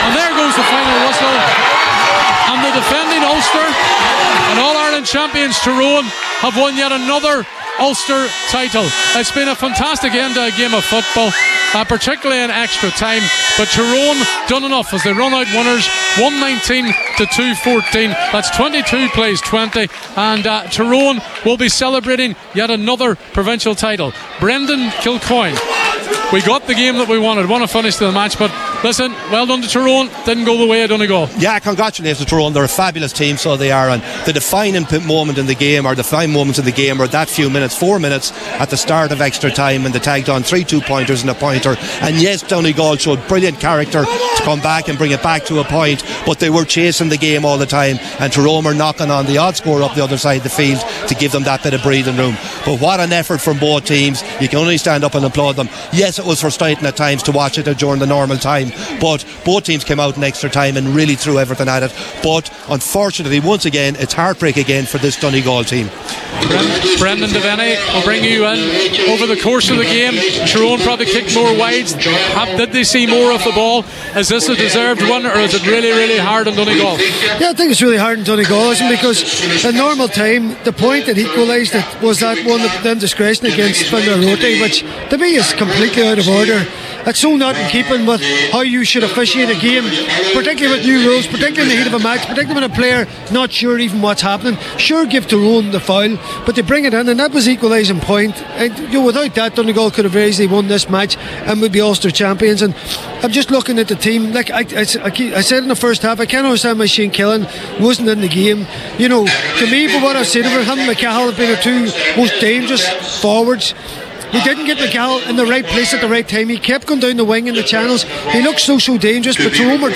Post-Match Reaction
were live at full time for Highland Radio Sport…